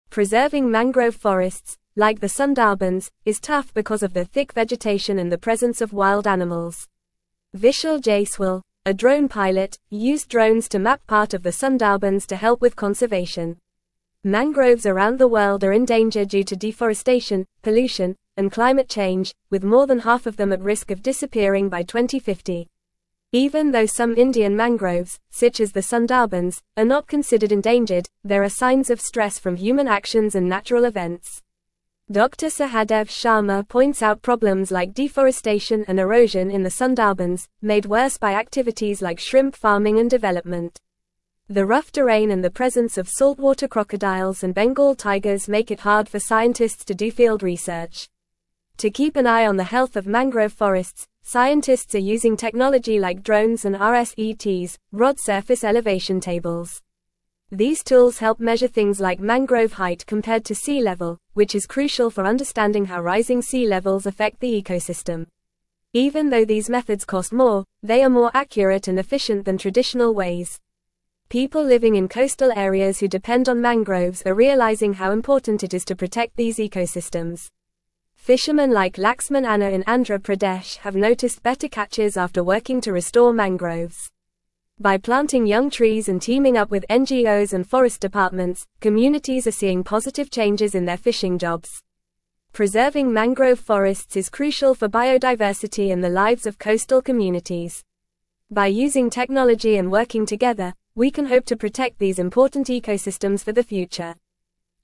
Fast
English-Newsroom-Upper-Intermediate-FAST-Reading-Mapping-Sundarbans-Drones-Preserve-Worlds-Largest-Mangrove-Forest.mp3